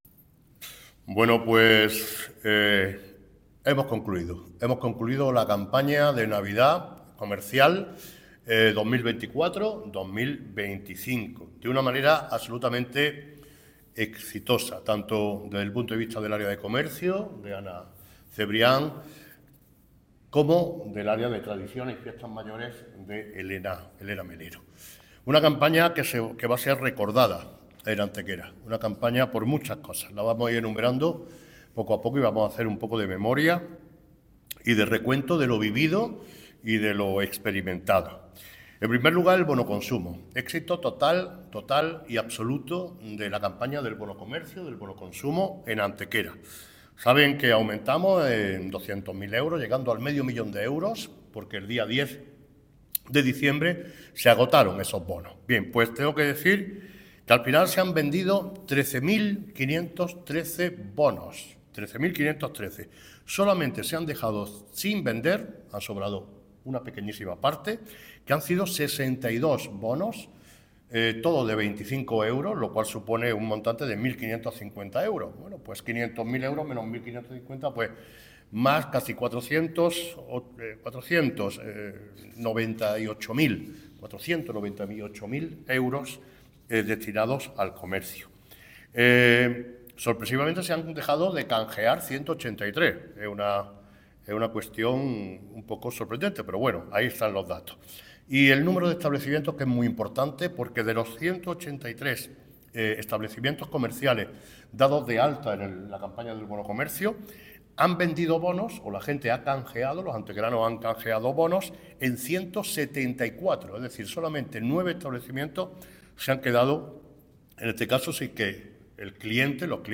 El alcalde de Antequera, Manolo Barón, ha presentado el balance de la Campaña de Navidad 2024-2025 en Antequera, calificándola como "un éxito absoluto" en todos los ámbitos.
Cortes de voz